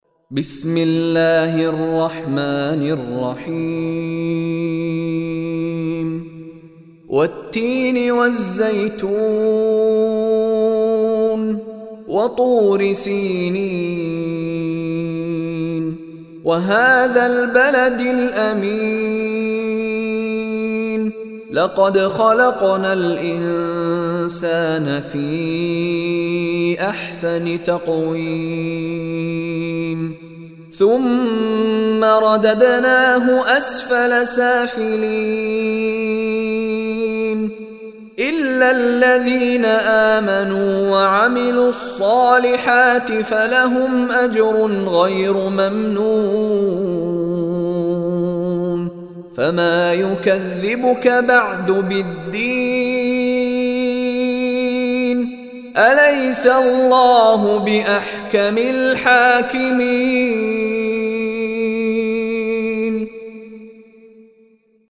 Surah Tin Online Recitation by Mishary Rashid
Surah Tin is 95th chapter of Holy Quran. Surah Tin listen online mp3 recited in Arabic or download audio mp3 in the voice of Sheikh Mishary Rashid Alafasy.